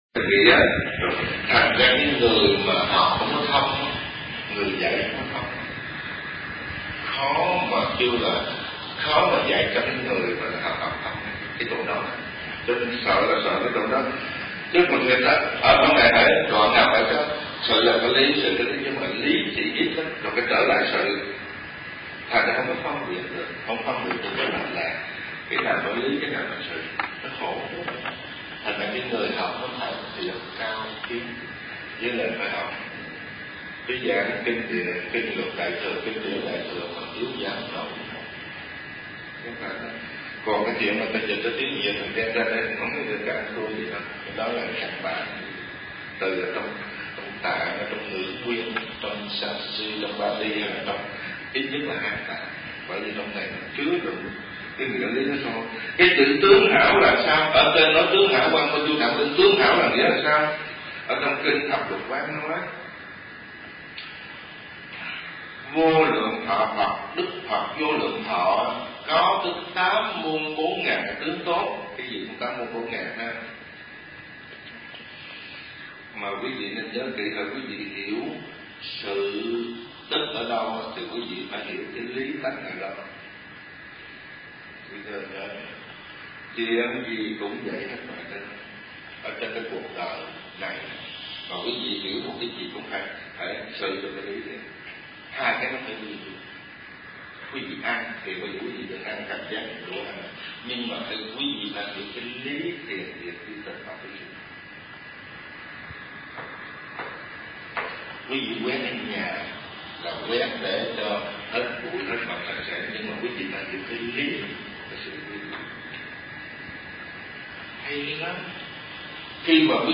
Kinh Giảng Nhị Khóa Hiệp Giải